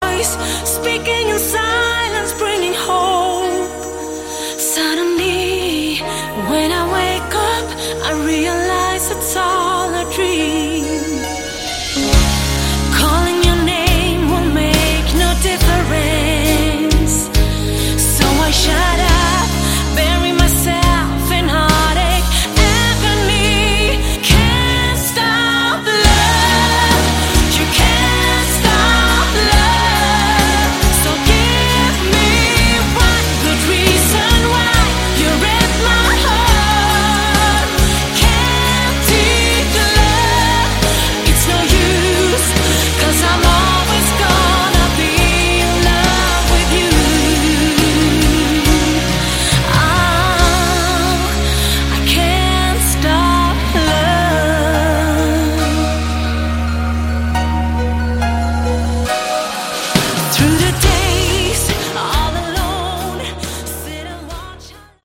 Category: Melodic Rock
lead and backing vocals
guitars
drums, keyboards
bass